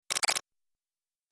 دانلود صدای ربات 60 از ساعد نیوز با لینک مستقیم و کیفیت بالا
جلوه های صوتی